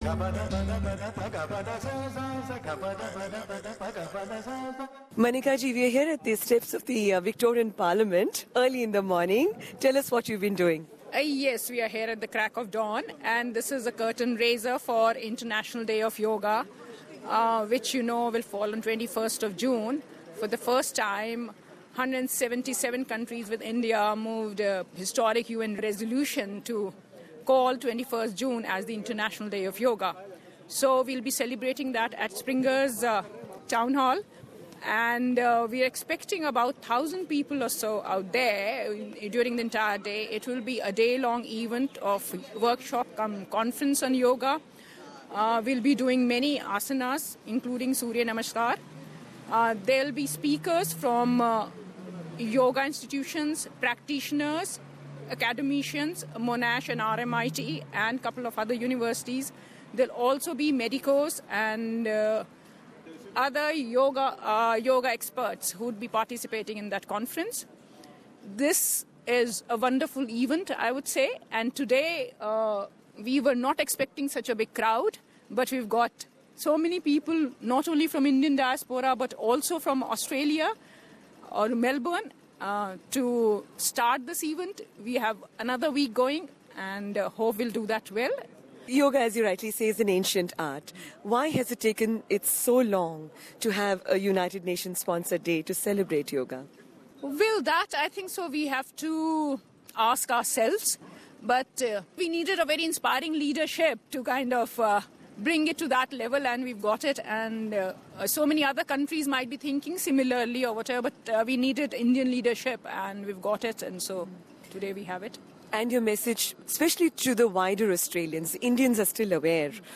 Here is a brief interview with the Hon Consul General about preparations for the upcoming International Day of Yoga...